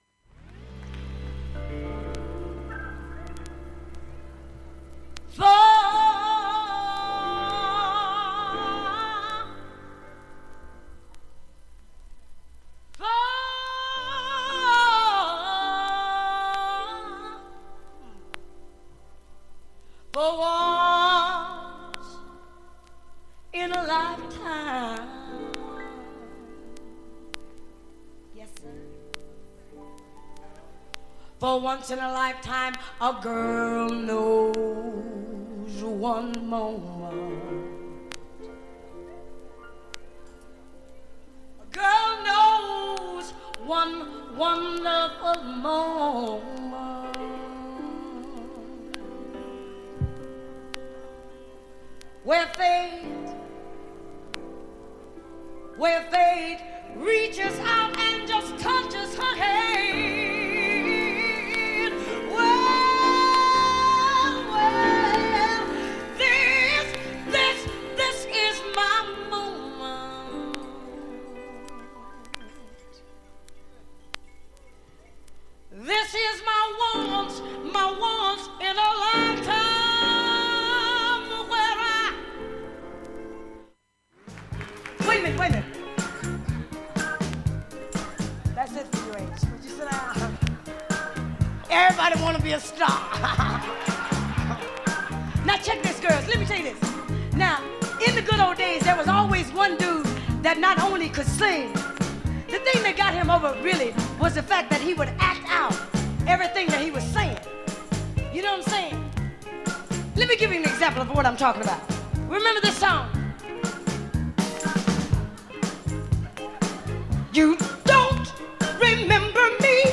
アットホームなライブアルバム。